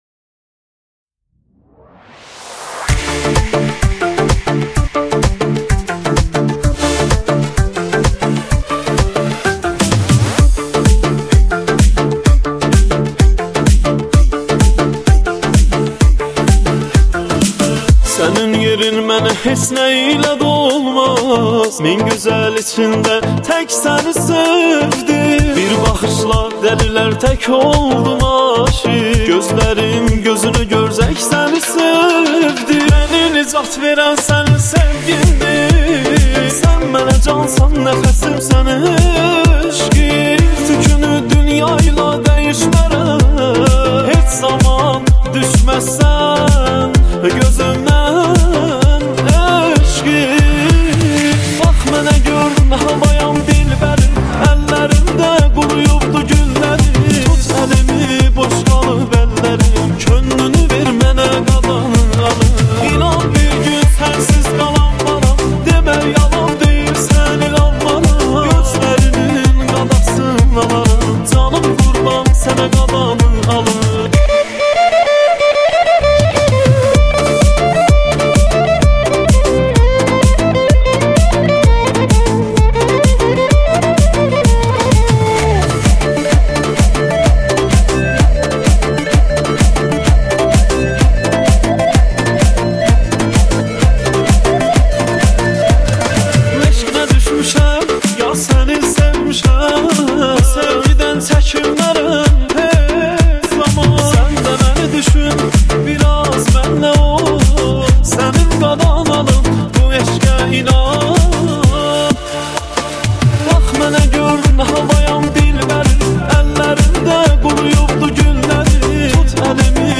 آهنگ شاد آذری برای رقص / آهنگ ترکی شاد جدید